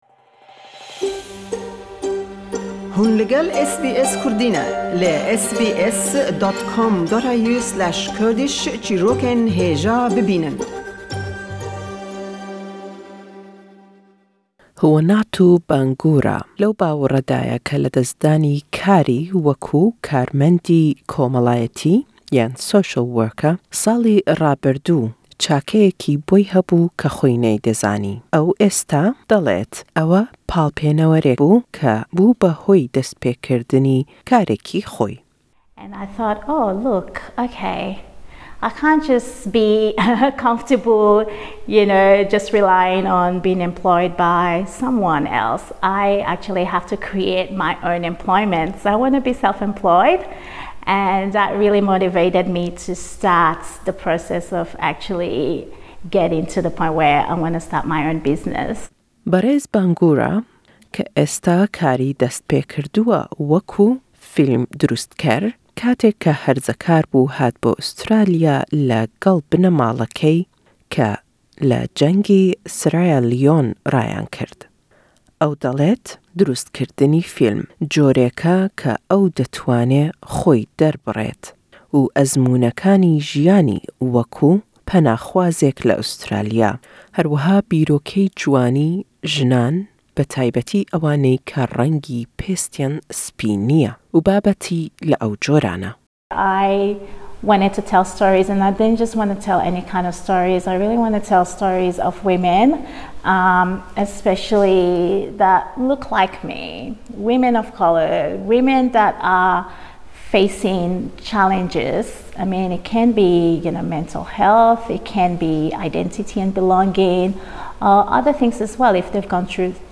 Lêkollînewe nîşan dedat egerî zortir heye ke jinanî koçber le Australya bê kar bin, mûçey kemtir werbigrin, sererayî helgirî birwanamey beriz bo ew karaney ke deyken. SBS le gell dû jinî entrepreneur diwa sebaret be dest-pêkirdinî karî xoyan, destkewtinî serbexoyî abûrî.